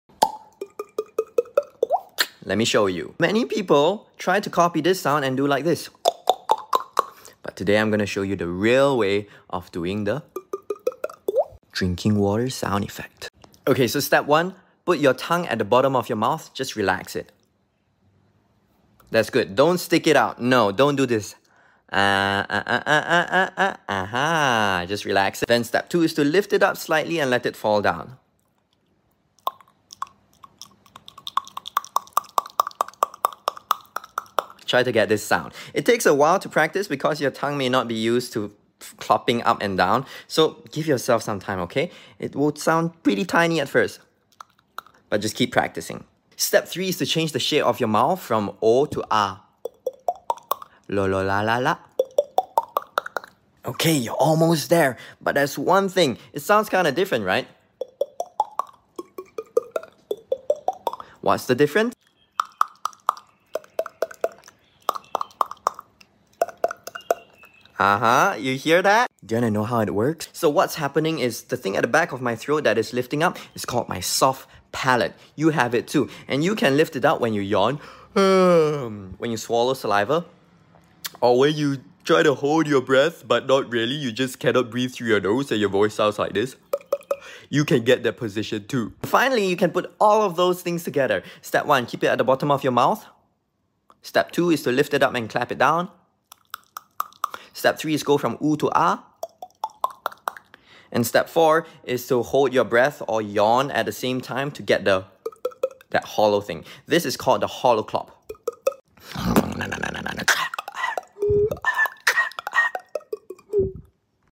Drinking water sound beatbox tutorial